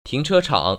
[tíngchēchăng] 팅처창  ▶